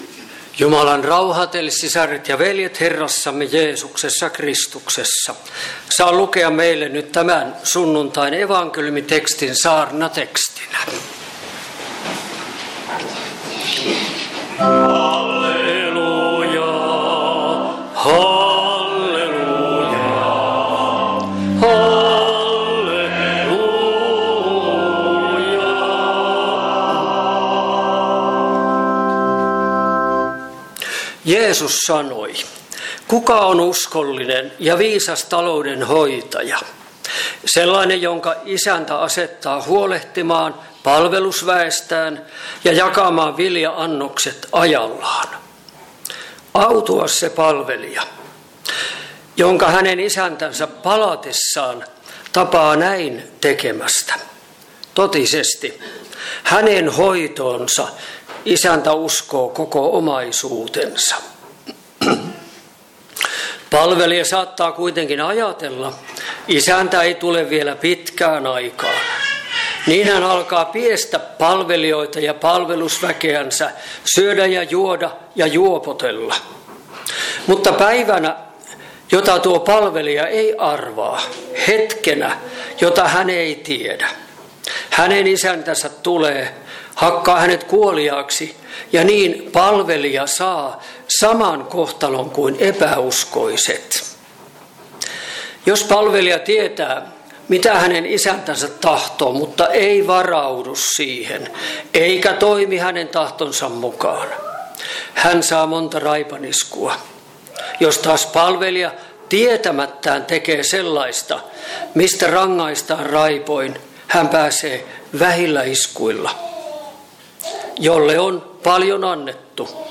Kokoelmat: Seinäjoki Hyvän Paimenen kappelin saarnat